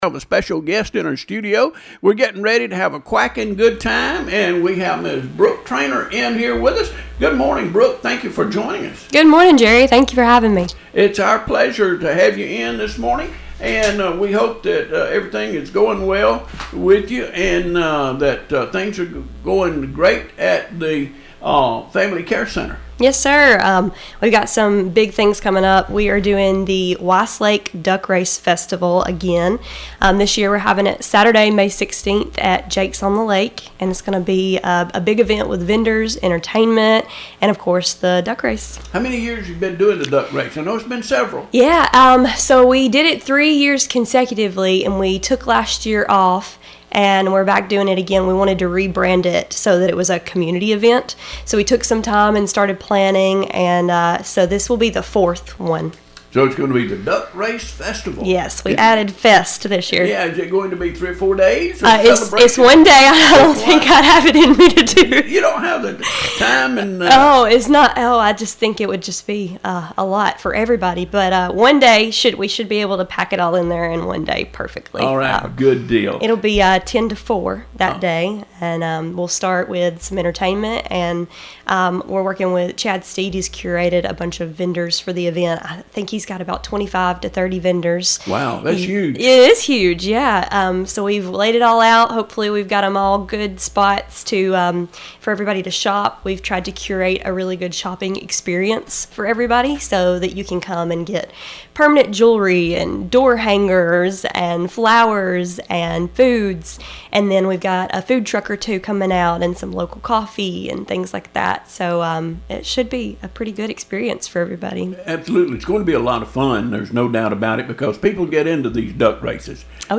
Family Care Center